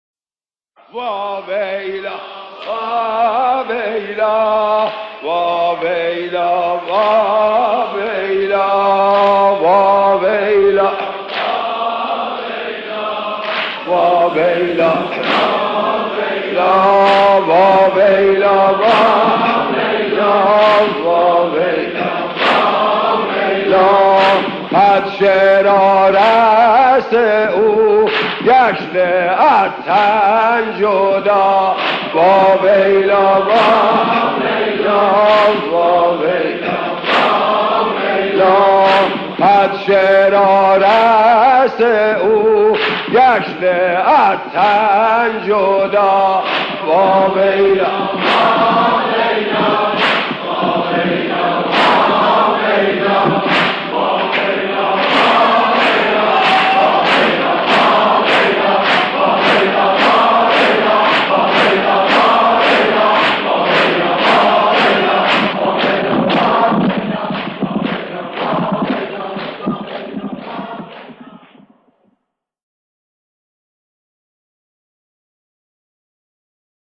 دم سینه‌زنی از زبان حضرت رقیه(س)